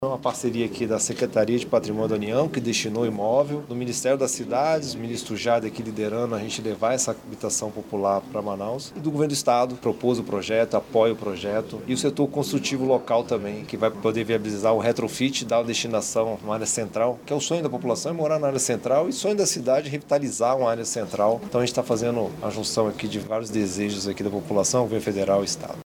Durante o encontro, o secretário executivo do Ministério das Cidades, Hailton Madureira, destacou que a requalificação do prédio é fruto de uma parceria entre os Governos Estadual e Federal.